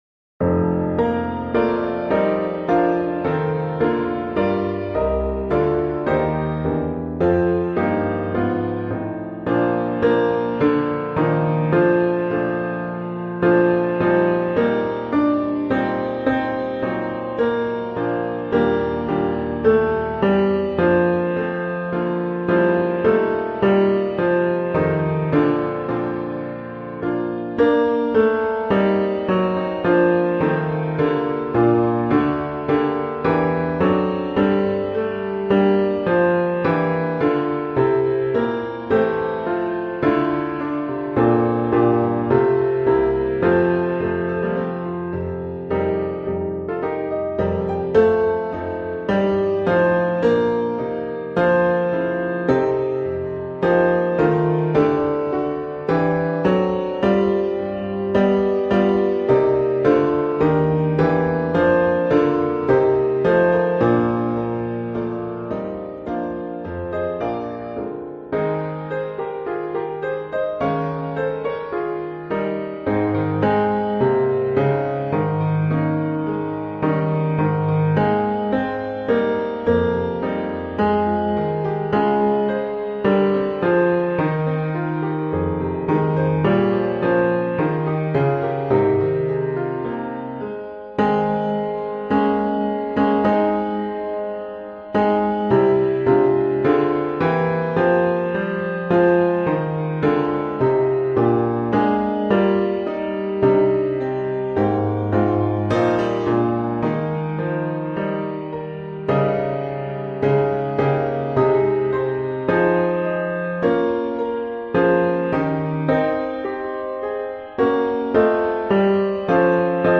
O Zion, Haste – Bass
O-Zion-Haste-Bass.mp3